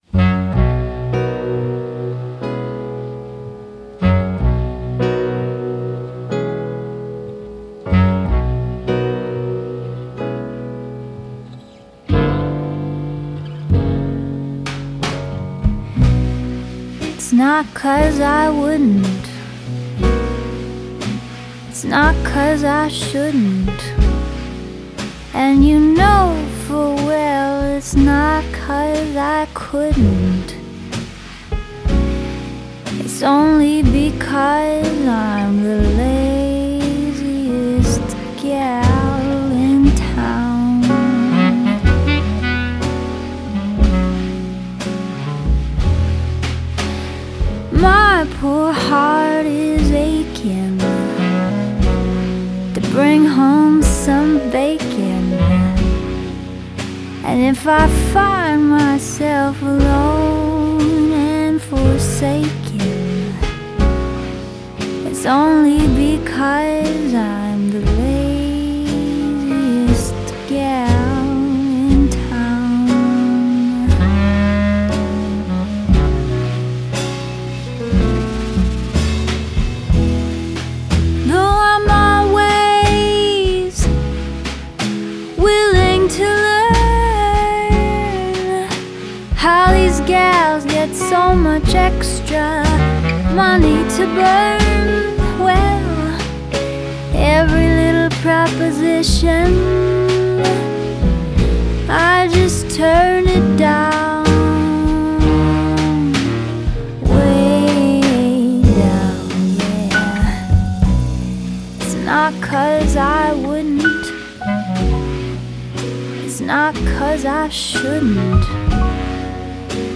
it’s lazy, it’s lilting, it’s lovely.
Tags2010s 2014 Eastern US Jazz Southern US